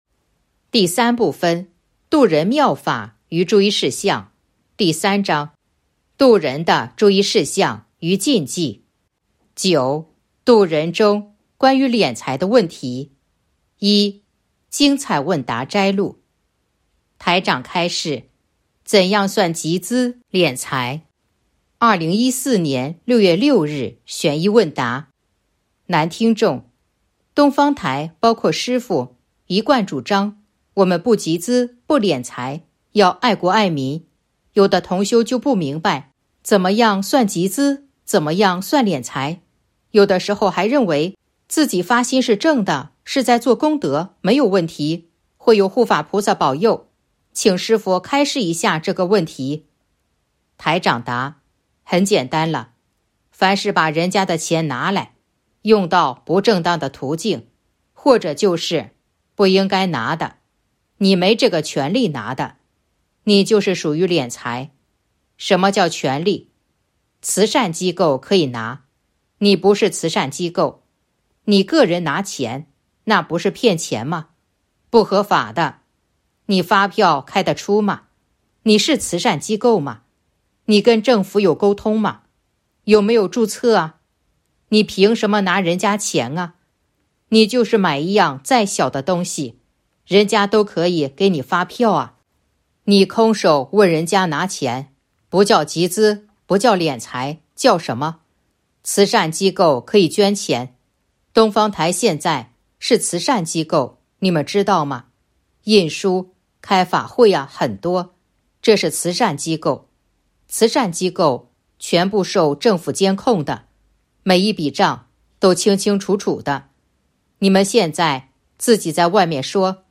064.1. 精彩问答摘录《弘法度人手册》【有声书】